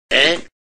Ehhh Sound
meme